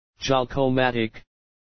Joucomatic英文读“捷扣曼蒂克”，可点击以下Joucomatic的在线英文读音：
Joucomatic英文读音